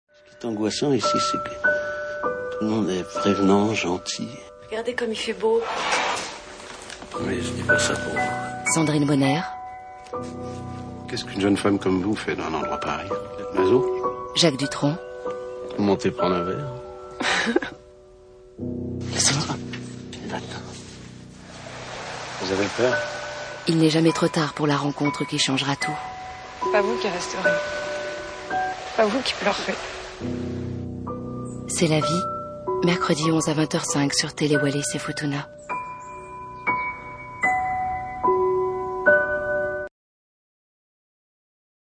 Comédienne, voix off, voix méduim, naturelle et dynamique, Publicitès, Voices Over ou institutionnels
Sprechprobe: Sonstiges (Muttersprache):
Female Voice, Méduim, Smiling, natural and dynamic! Advertising, Game, Voice Over and more...since 18 years!